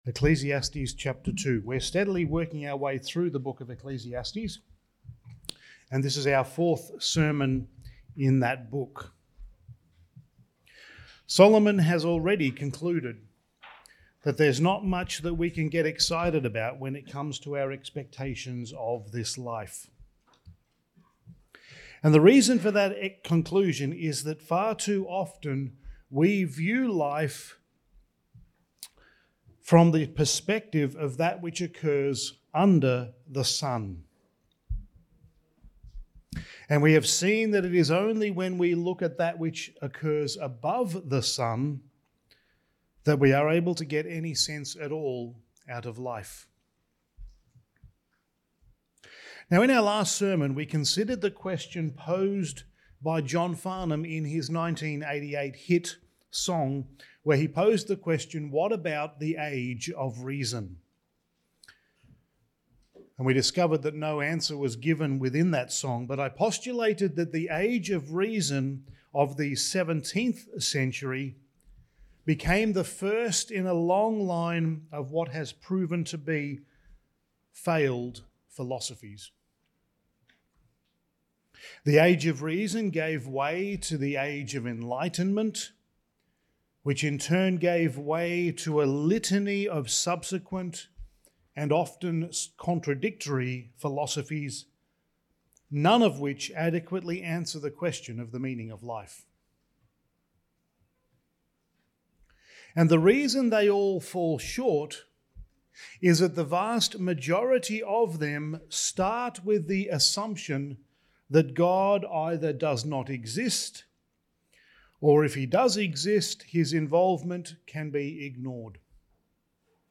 Studies in the Book of Ecclesiastes Sermon 4: The Vanity of Pleasure and Possessions
Service Type: Sunday Morning